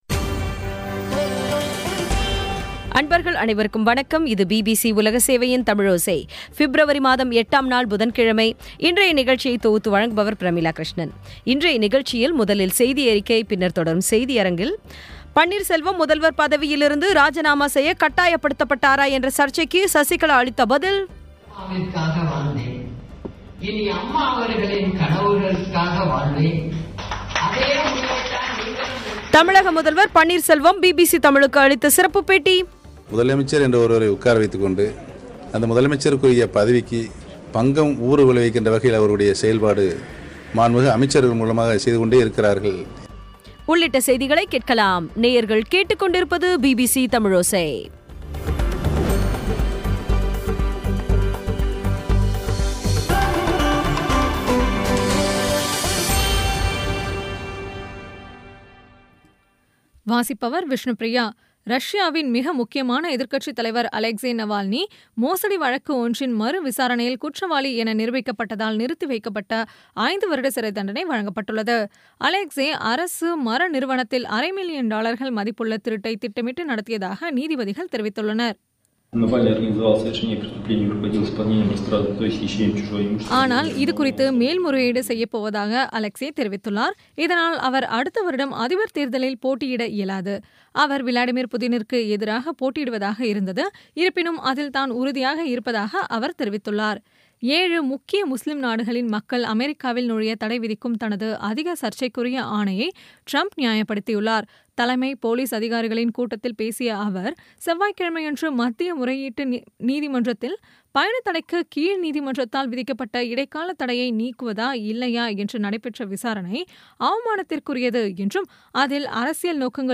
இன்றைய நிகழ்ச்சியில் முதலில் செய்தியறிக்கை, பின்னர் தொடரும் செய்தியரங்கில் பன்னீர்செல்வம் முதல்வர் பதவியிலிருந்து ராஜிநாமா செய்ய கட்டாயப்படுத்தபட்டாரா என்ற சர்ச்சைக்கு சசிகலா அளித்த பதில் முதல்வர் பன்னீர்செல்வம் பிபிசி தமிழுக்கு அளித்த சிறப்புப் பேட்டி ஆகியவை கேட்கலாம்